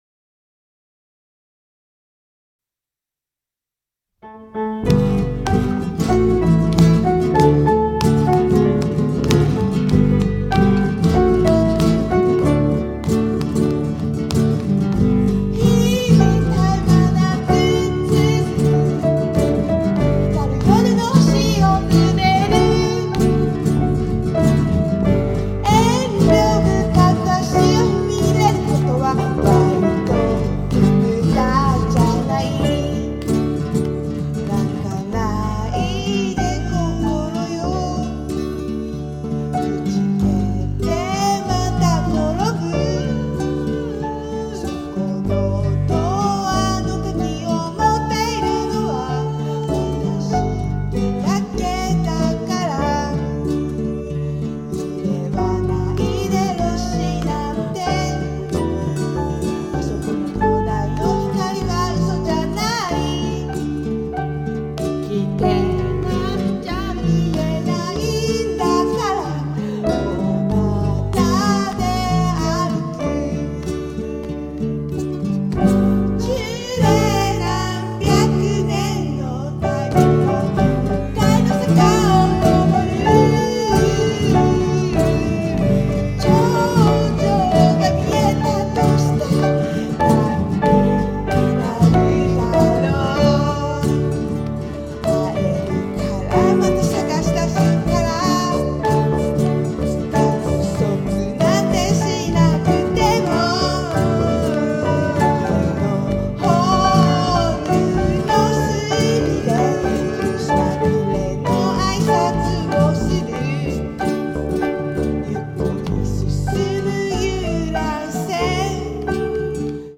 荒々しいギターに物凄くキャッチーなメロディ